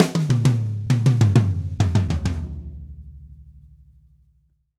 Drumset Fill 12.wav